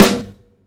FDG_SNR.wav